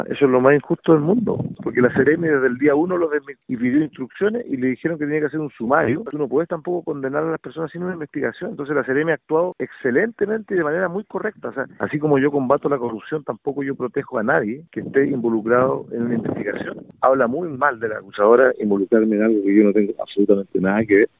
En tanto, el senador Fidel Espinoza (PS) señaló que si bien junto al acusado militan por el mismo partido, negó cualquier respaldo por la indagatoria.